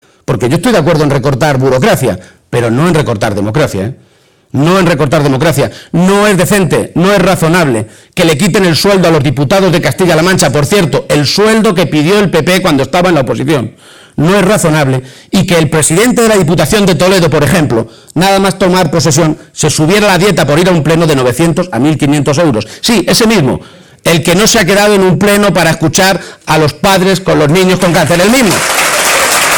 El secretario general del PSOE de Castilla-La Mancha, Emiliano García-Page, ha aprovechado que hoy ofrecía un mitin en la provincia de Cuenca para contestar algunas de las cosas que dijo Cospedal en el acto que celebró el pasado domingo en la capital conquense y señalar que votar al PSOE para que pierda el PP es hacerlo también contra los recortes y la mentira.